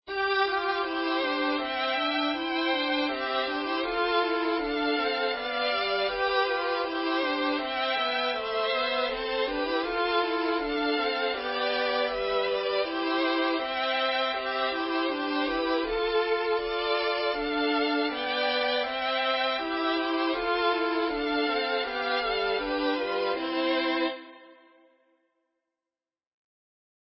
birdsong.mp3